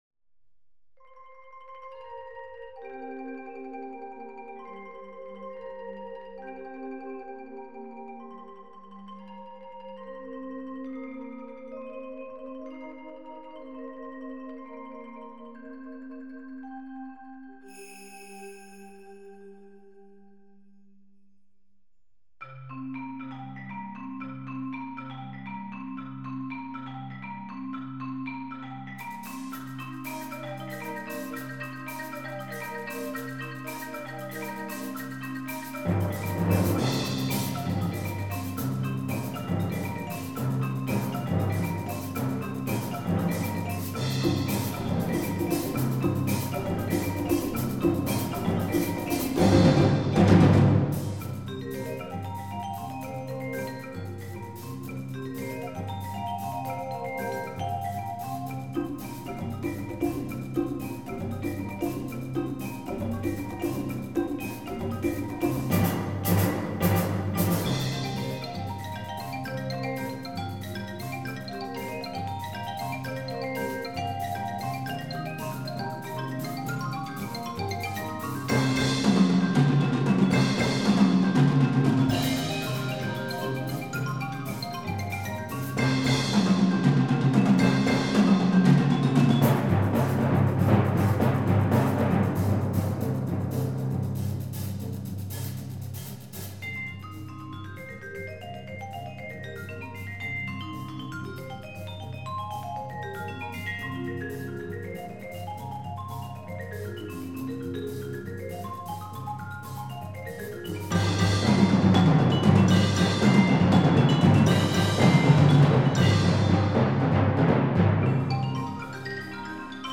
Bladmuziek voor flexibel ensemble.